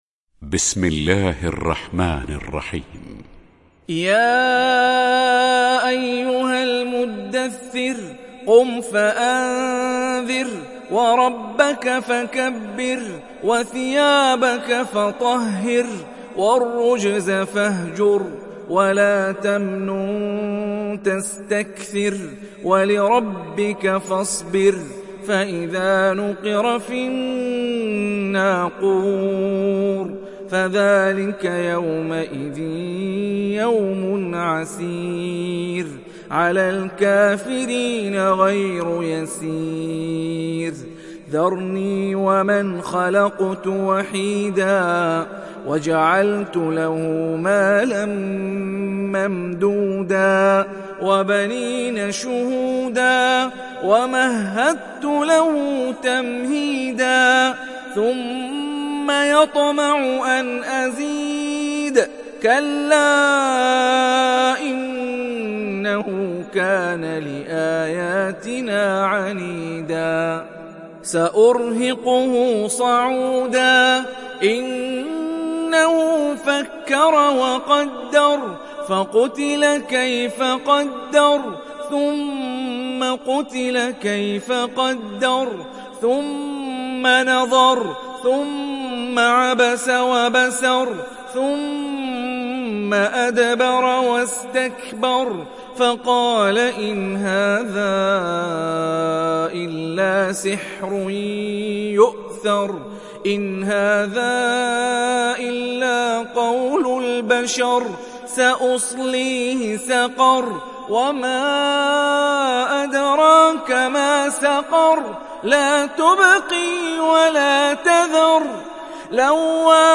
تحميل سورة المدثر mp3 بصوت هاني الرفاعي برواية حفص عن عاصم, تحميل استماع القرآن الكريم على الجوال mp3 كاملا بروابط مباشرة وسريعة